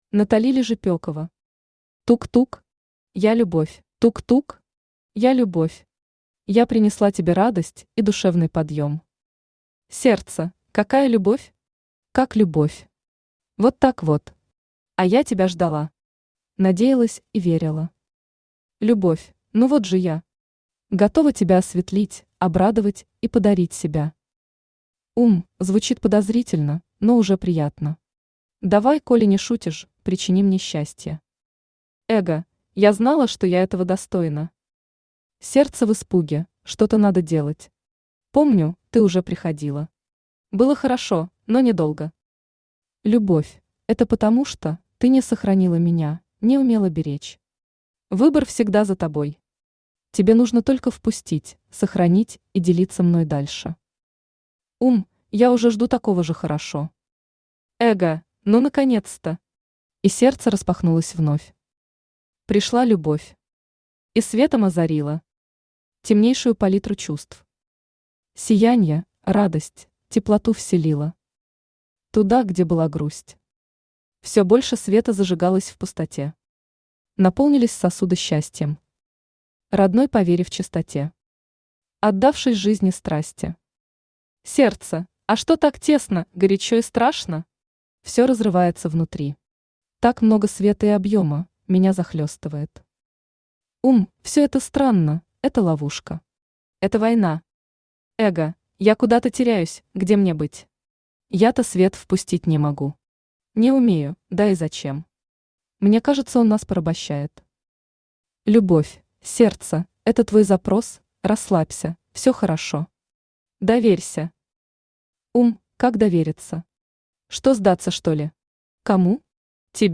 Аудиокнига Тук-тук! Я Любовь | Библиотека аудиокниг
Я Любовь Автор Натали Лежепёкова Читает аудиокнигу Авточтец ЛитРес.